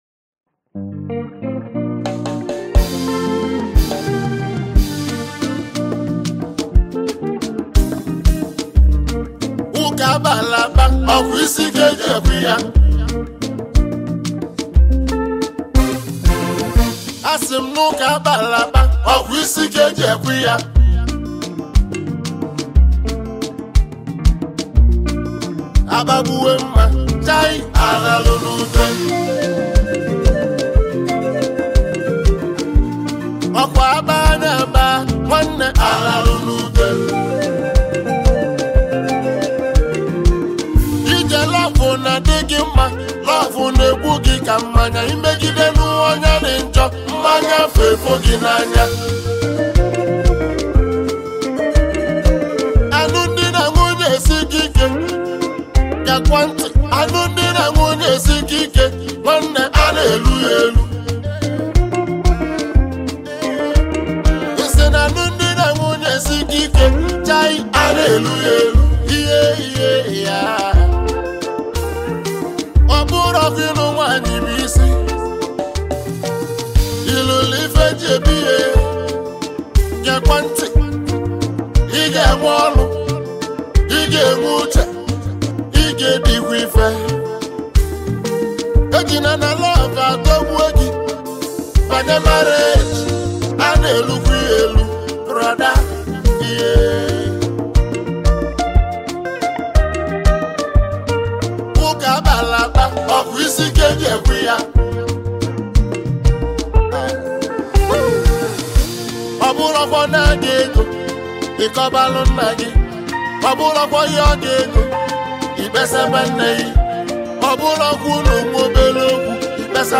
Highlife Music